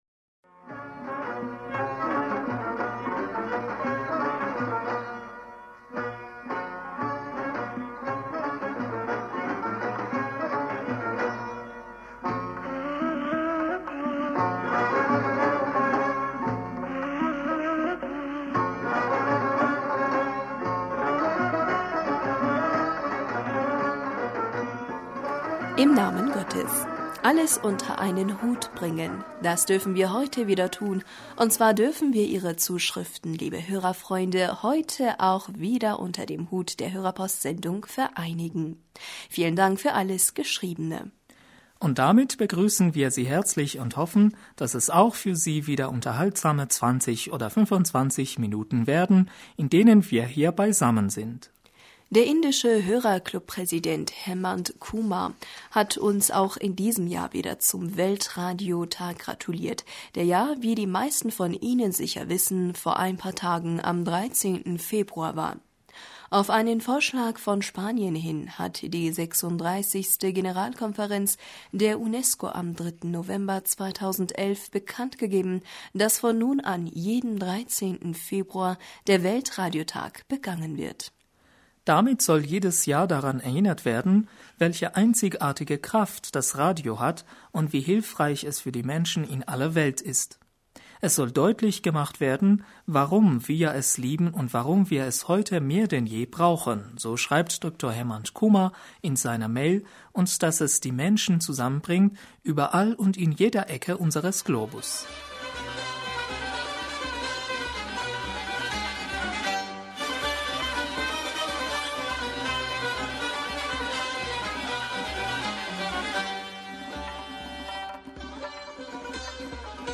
Hörerpostsendung am 19. Februar 2017 - Bismillaher rahmaner rahim -